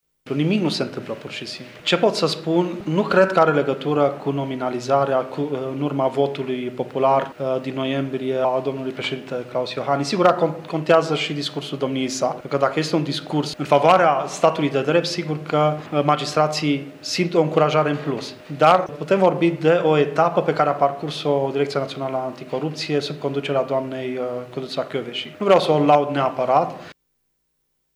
Membrul CSM, Horaţius Dumbravă, a declarat astăzi într-o conferinţă de presă la Tîrgu-Mureş că numărul mare de dosare care ajung în instanţă, după ani de stagnare, se datorează măsurilor de organizare a Direcţiei Naţionale Anticorupţie luate de Codruţa Kovesi: